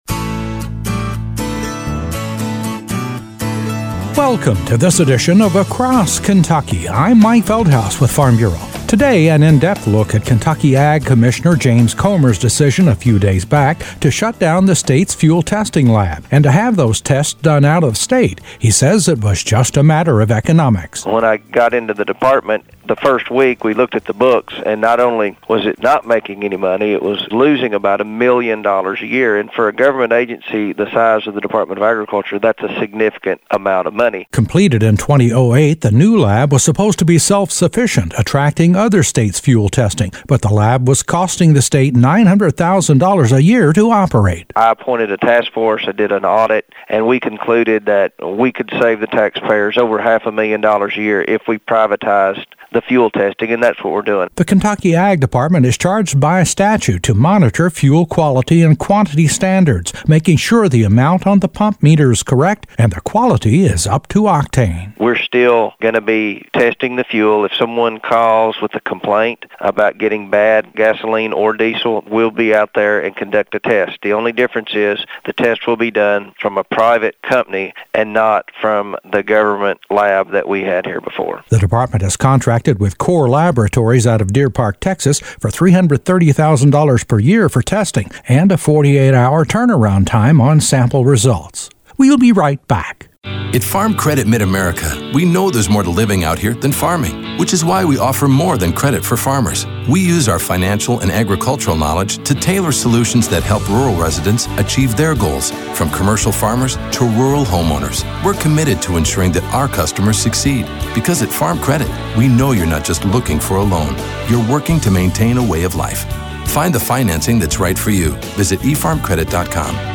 An in-depth report on Ky Ag Commissioner James Comer’s recent decision to close the state’s fuel testing lab.